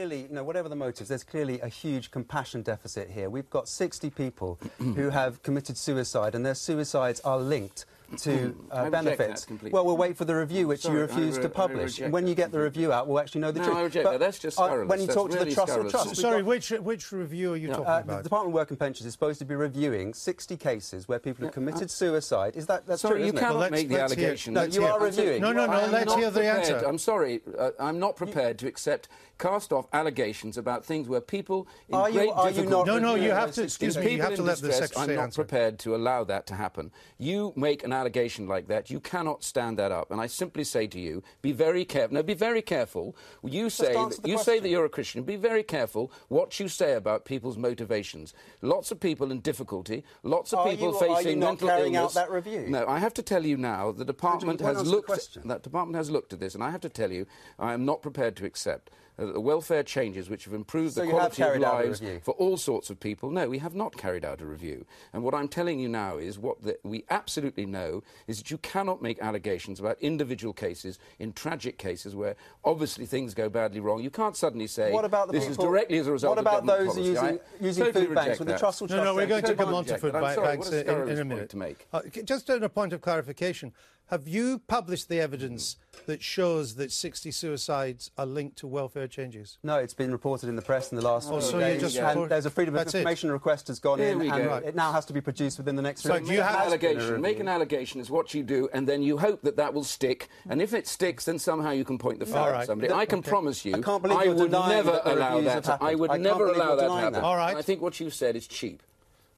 IDS and Jonathan Bartley clash on the effect of welfare reforms